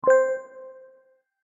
• Качество: 128, Stereo
громкие
без слов
короткие
звонкие
Рингтон на уведомление для Андроида